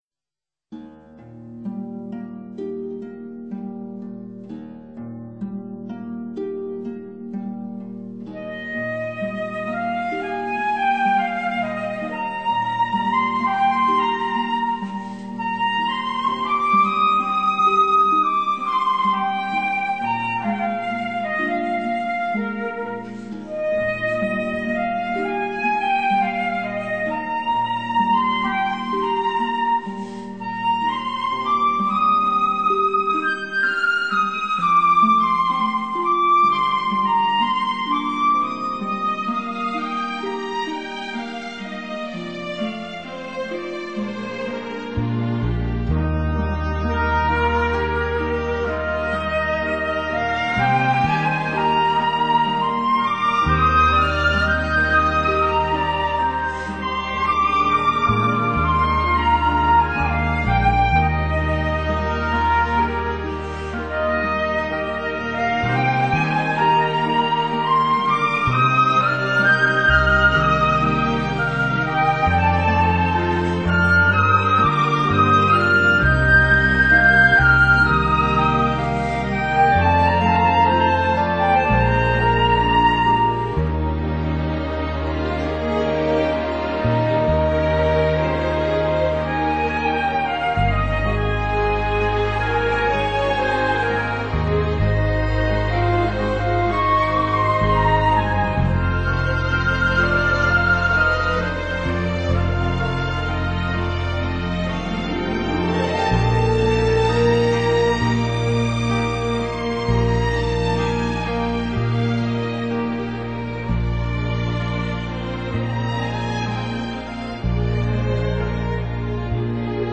透过现代感的编曲手法所伴随而来的高贵及愉悦